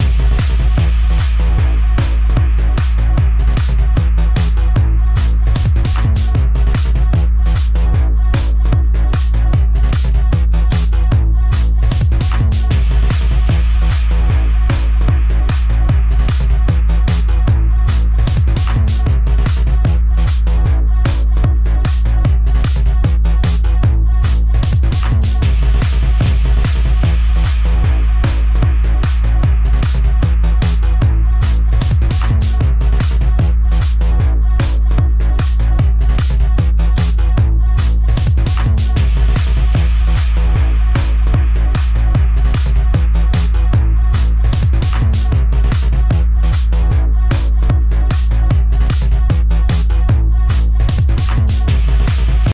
アップテンポなビートポップサウンド。かっこいい、ノリノリ、クール、盛り上がる、軽快。